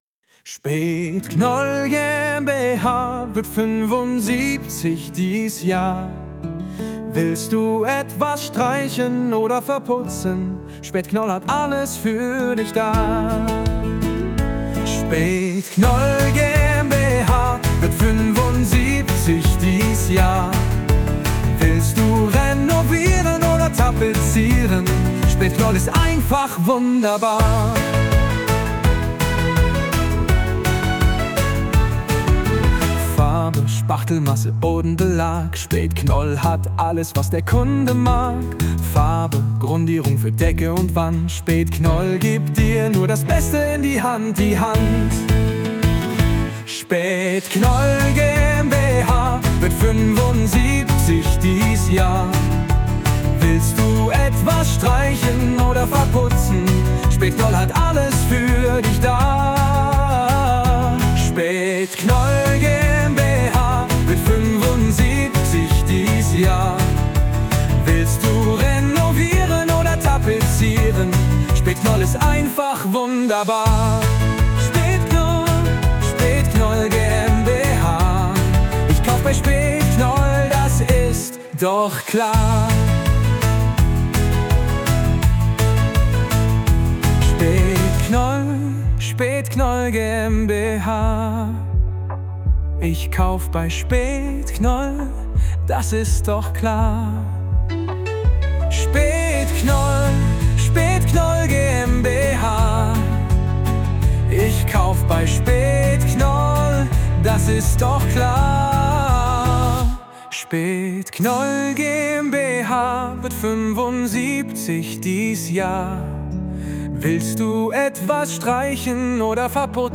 Mit Hilfe von KI erstellt.